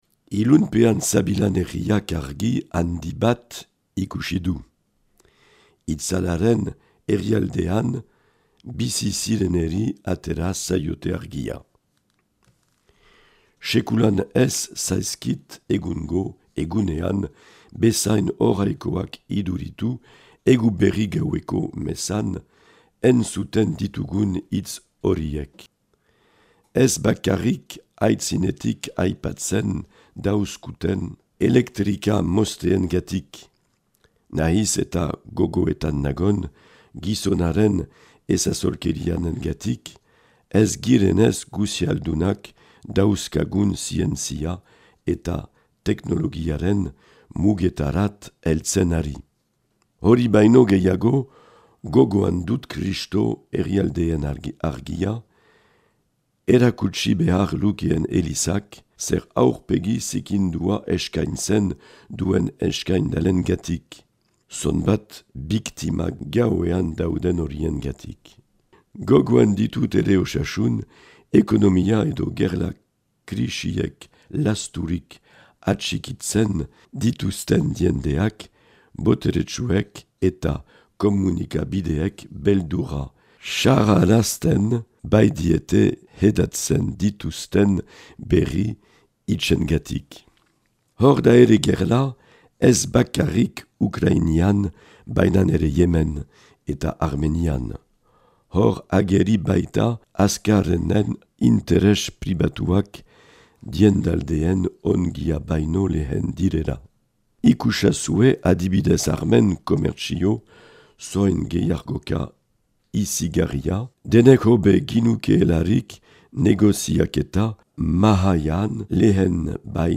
Marc Aillet, Baiona, Lezkar eta Olorongo apezpikua.